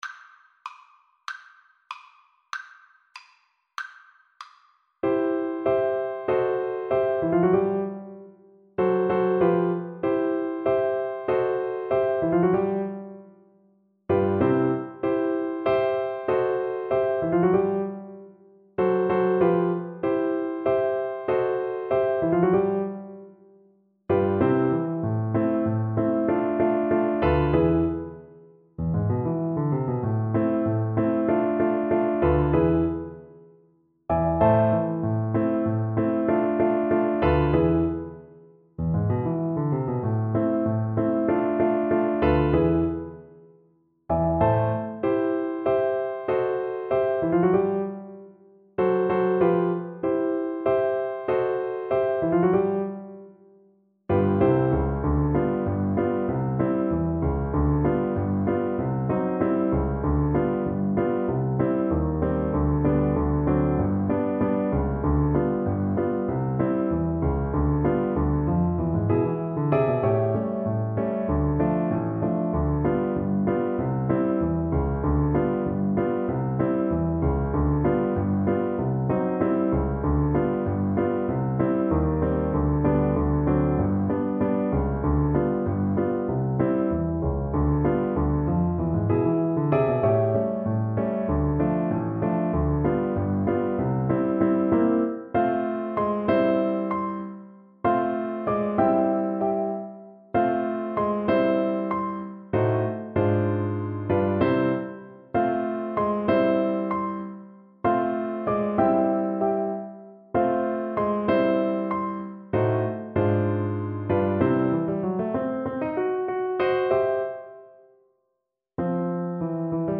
Jazz Scott Joplin Stoptime Rag Cello version
Cello
2/4 (View more 2/4 Music)
C major (Sounding Pitch) (View more C major Music for Cello )
Jazz (View more Jazz Cello Music)